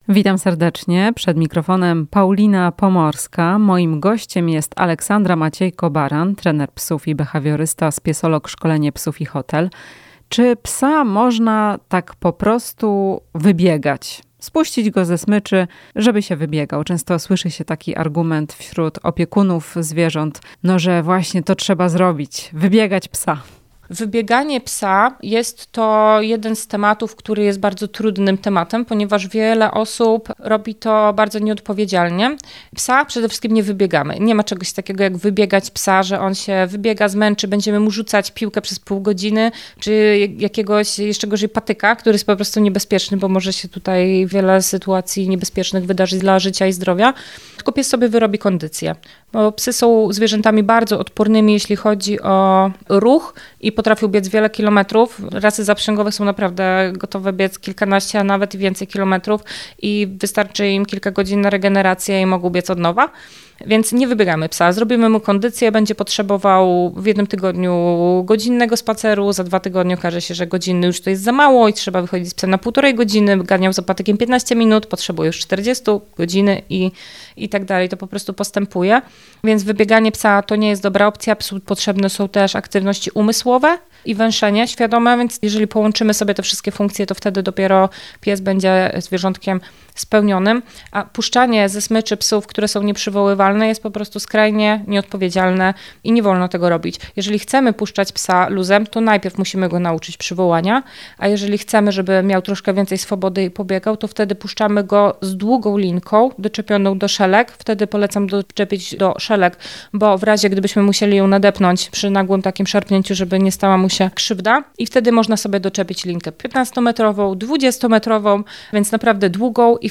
W „Chwili dla pupila” powiemy czy faktycznie trzeba „wybiegać psa”, jak twierdzi część opiekunów. Rozmowa